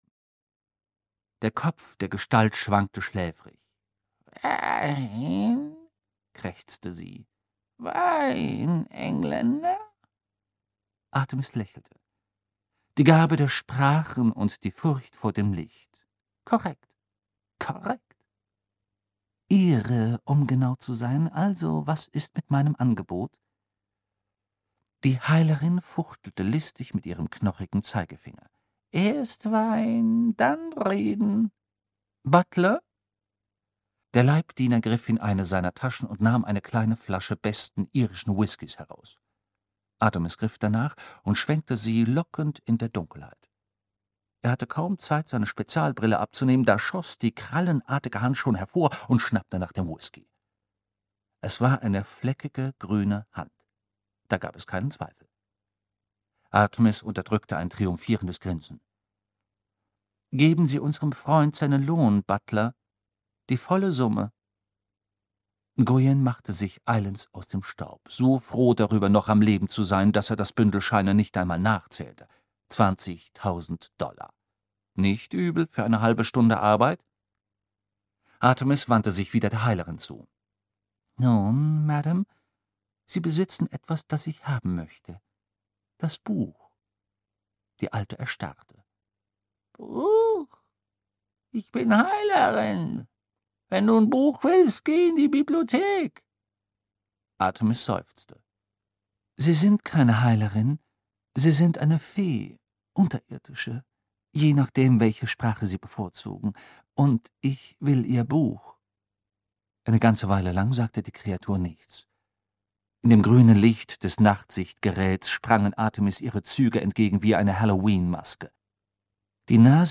Artemis Fowl (Ein Artemis-Fowl-Roman 1) - Eoin Colfer - Hörbuch